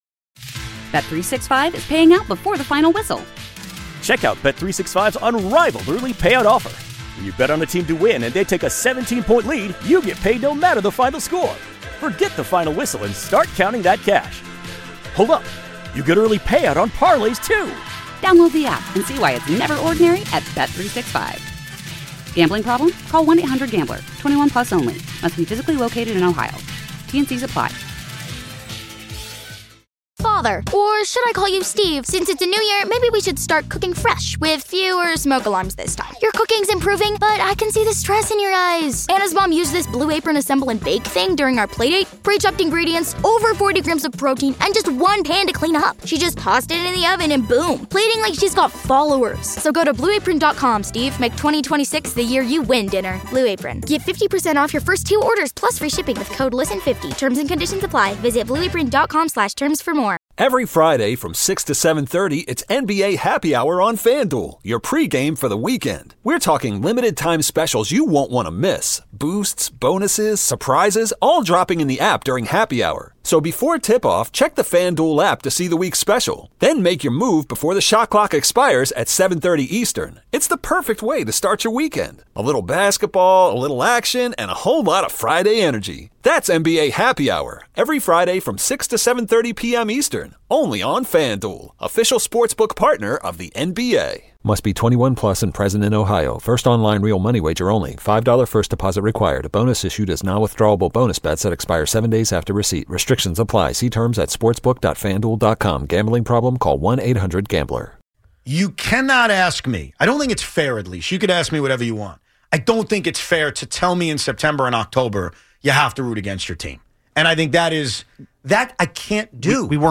RSS 🧾 Download transcript Summary A passionate debate erupts over tanking, fandom, and what fans are actually supposed to root for in September and October. The guys argue whether it’s ever acceptable to cheer for losses, how draft position really changes franchises, and why fans should never be told to embrace losing.